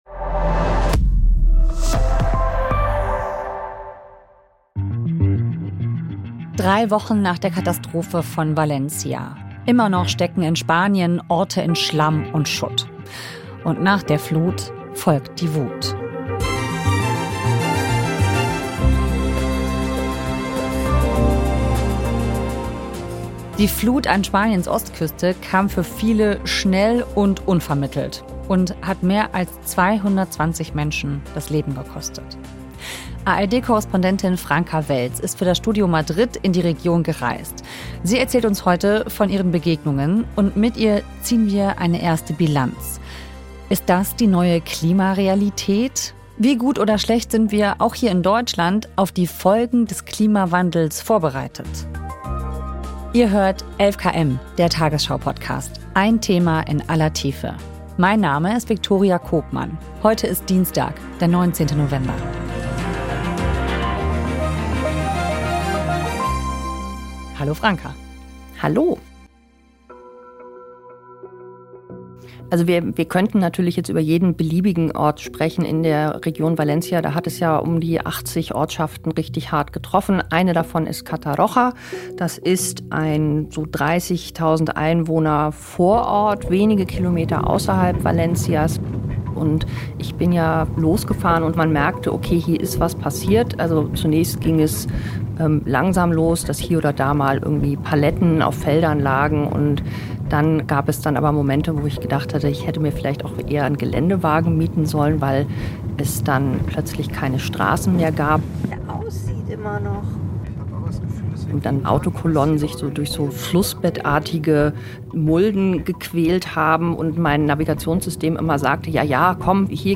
Sie erzählt bei 11KM von ihren Begegnungen.